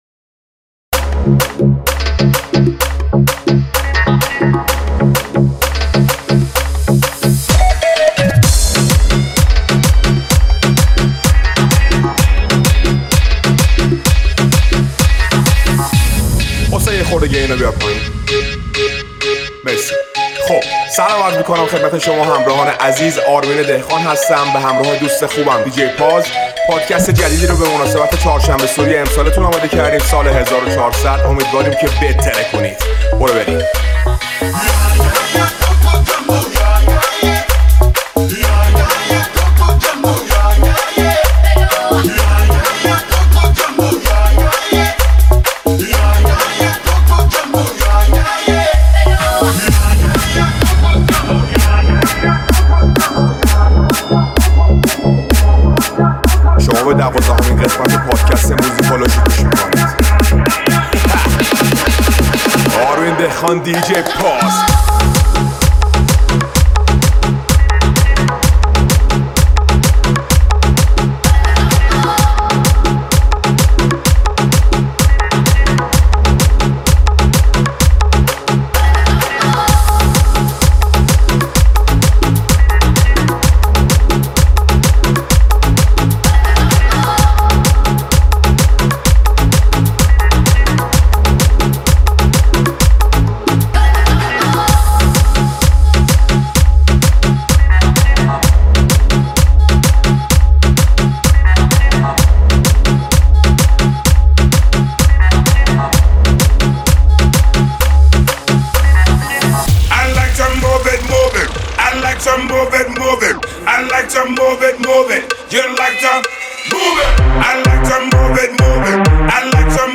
{ میکس آهنگ شاد خارجی مخصوص چهارشنبه سوری 1400 }